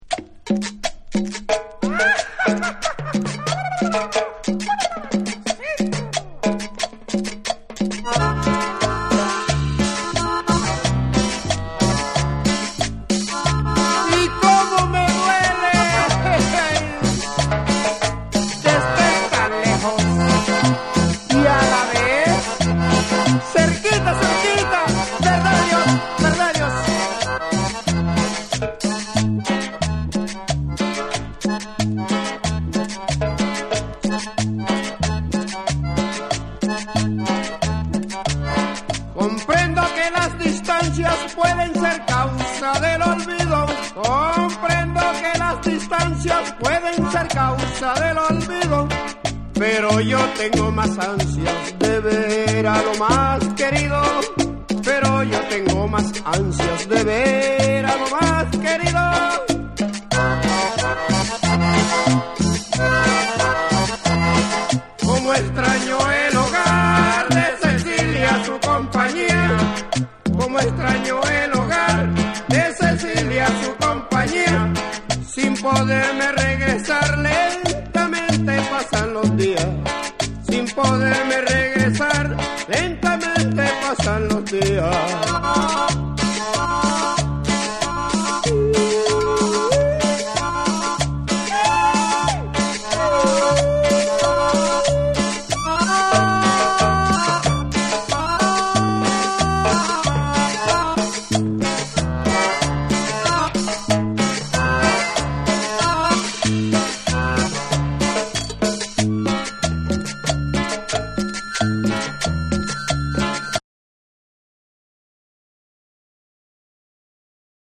WORLD / CUMBIA